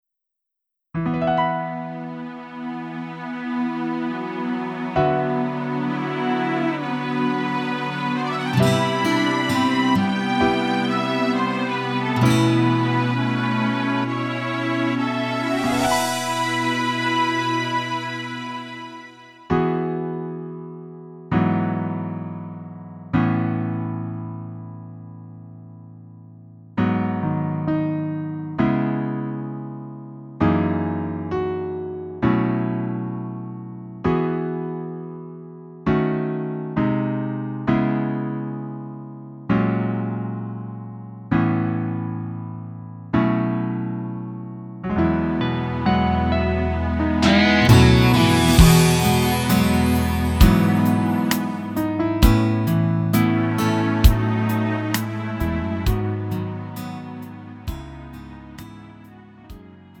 음정 -1키 4:13
장르 구분 Lite MR